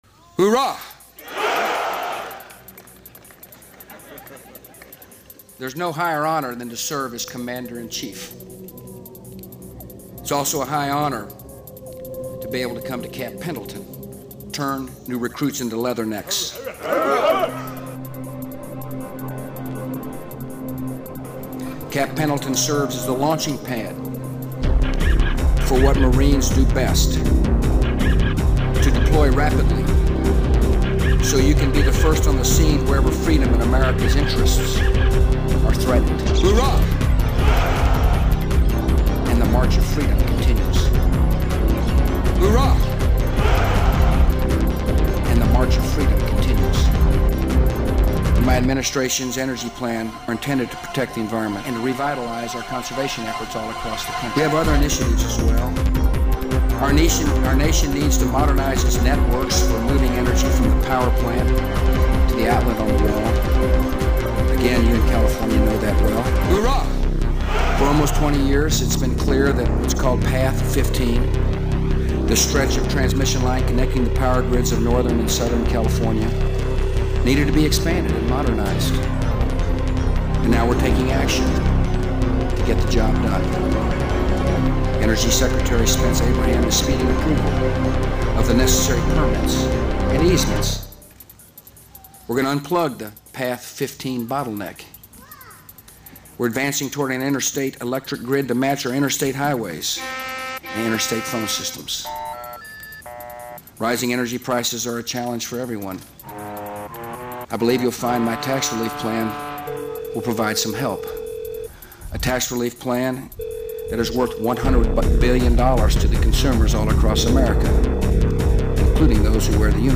A creepy combination of military rhetoric with power infrastructure discussion.